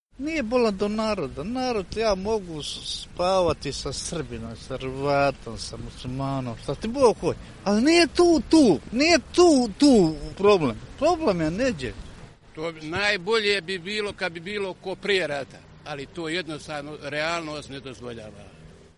Anketa: Mostar